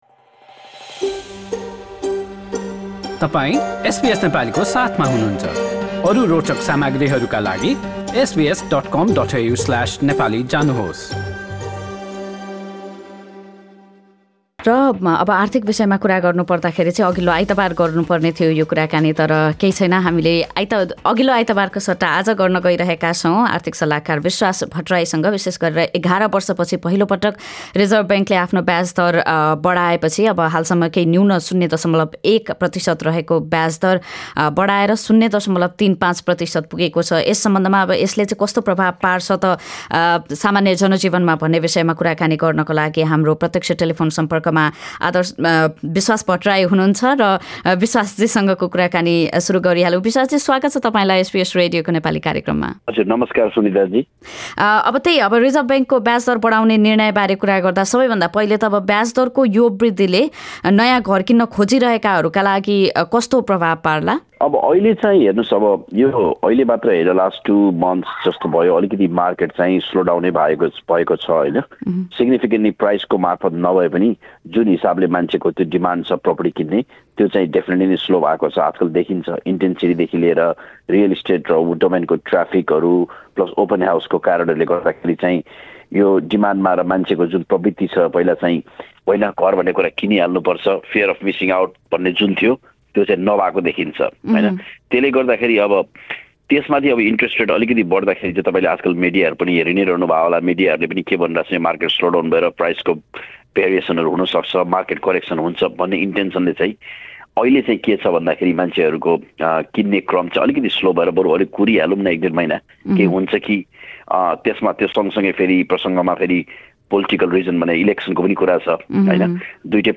आर्थिक कुराकानी हरेक महिनाको पहिलो आइतवार अपराह्न ४ बजेको कार्यक्रममा प्रत्यक्ष प्रसारण हुन्छ।